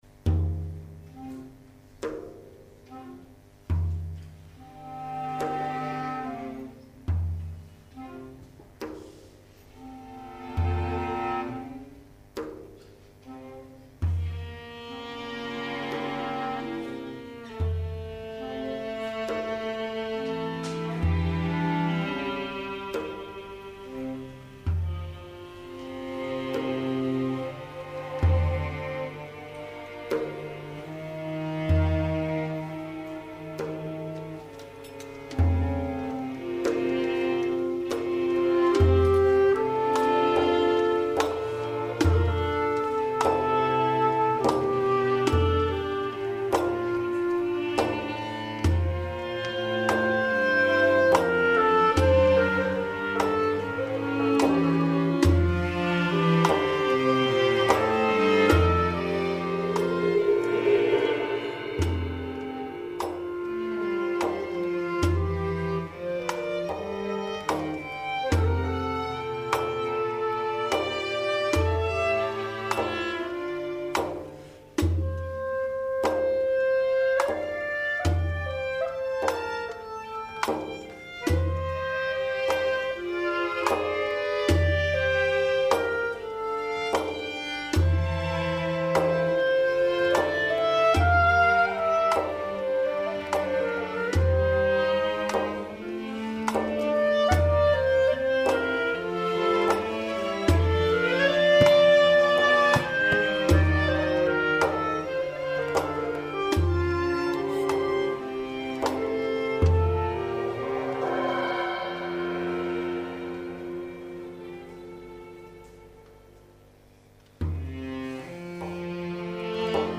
الموسيقى المصهورة
المقطوعة الموسيقية الاولى :